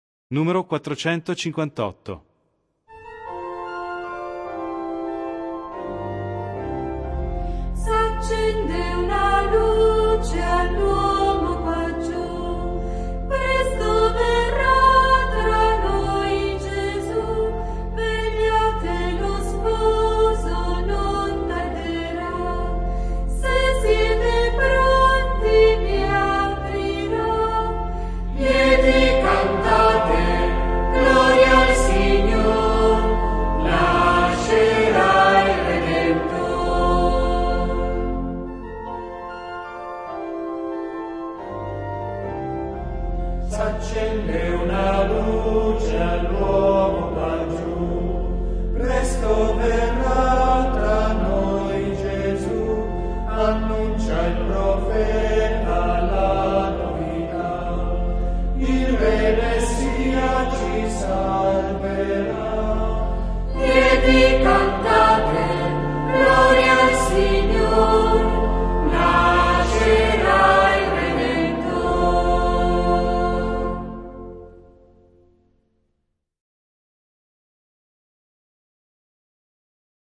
In questo tempo di emergenza sanitaria vogliamo sfruttare la tecnologia e proporvi una PROVA DEI CANTI VIRTUALE utllizzando gli mp3 qui sotto riportati, ad uso dell'Assemblea.